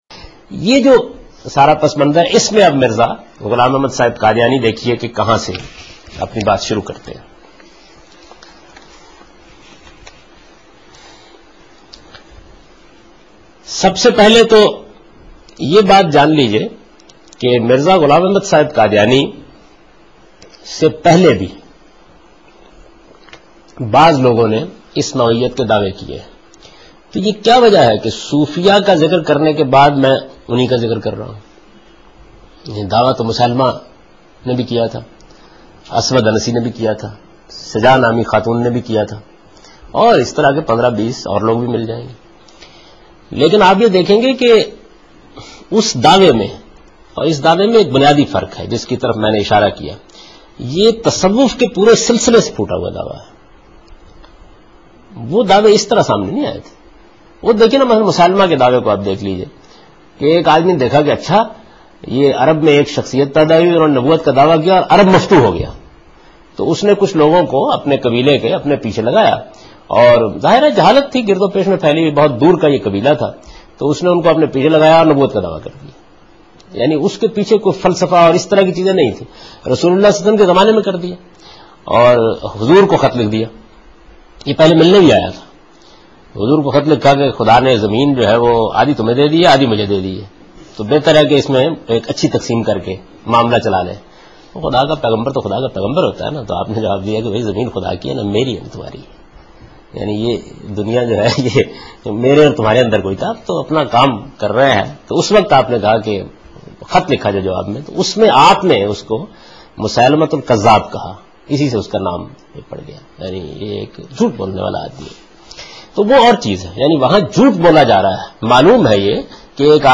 Javed Ahmed Ghamidi: From Meezan Lectures